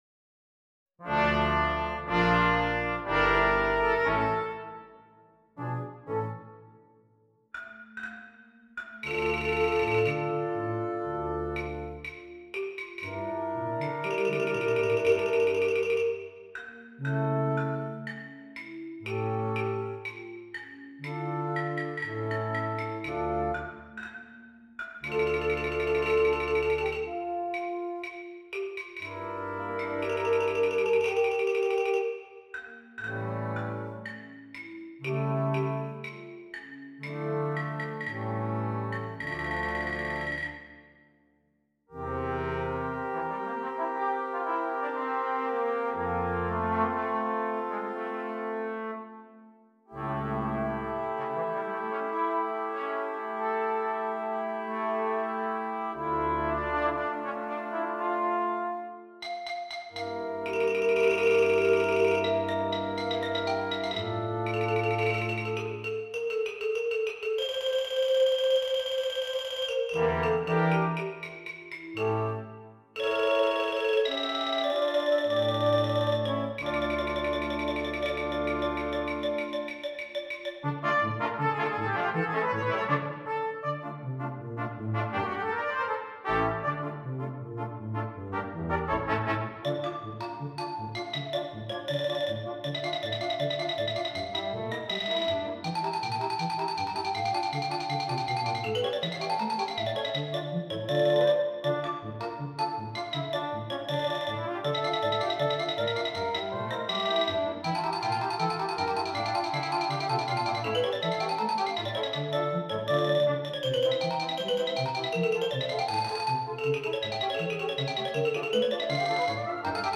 Brass Quintet and Solo Xylophone